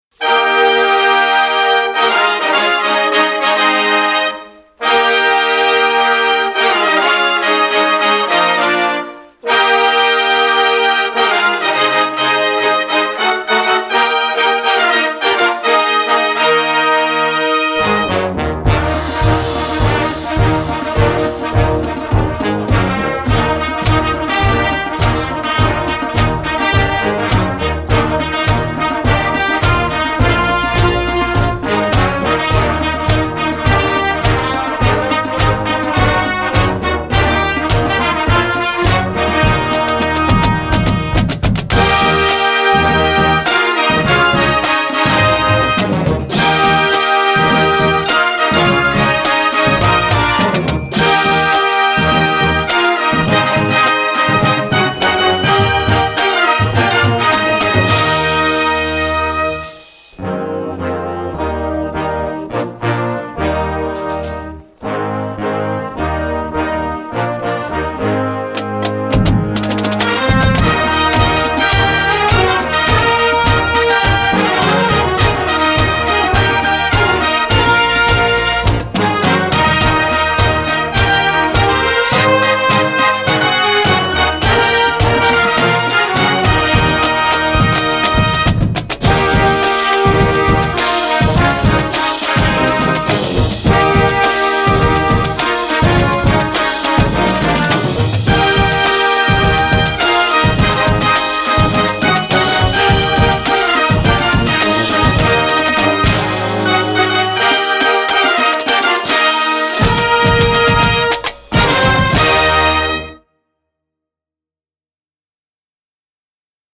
Marching Band
Besetzung: Blasorchester